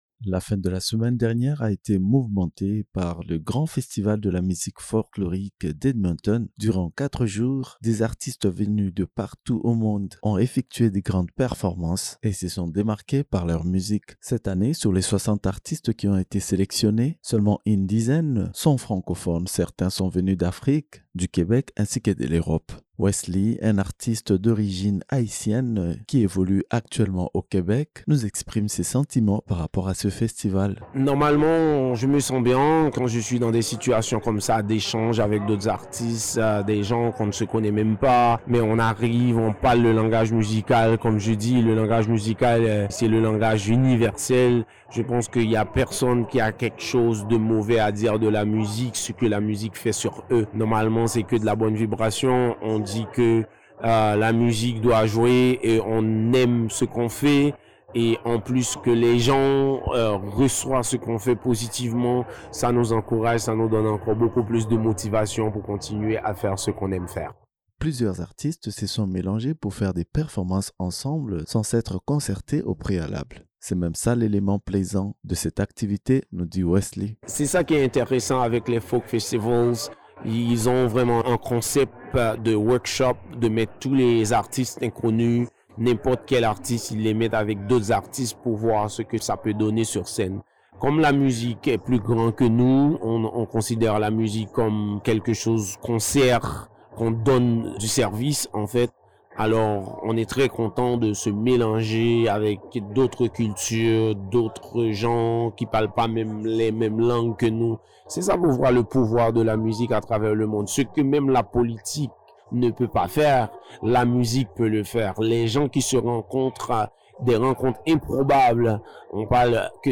Entrevue-folk-fest-23_mixdown.mp3